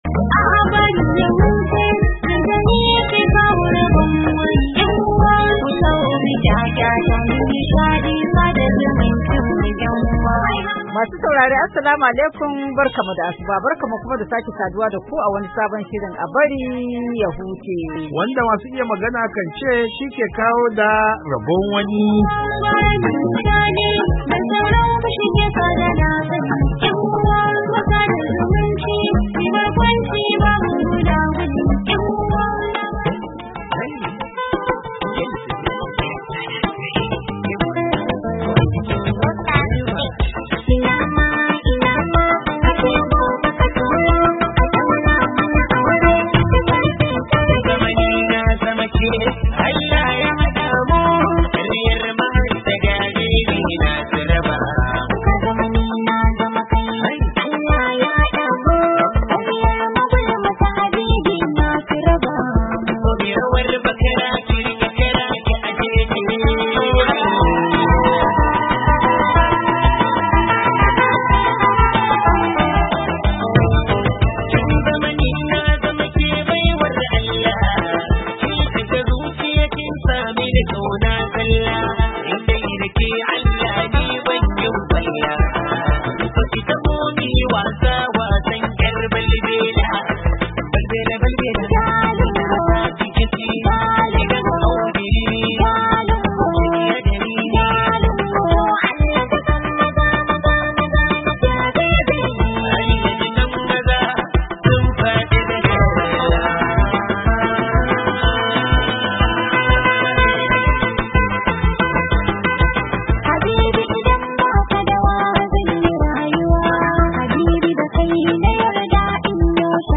A BARI YA HUCE: Tattaunawa kan yadda babban zaben Amurka ya kasance, inda Amurkawa suka zabi Donald Trump a matsayin sabon shugaban kasa da zai kama aiki a ranar 20 ga watan Janairun shekara mai zuwa.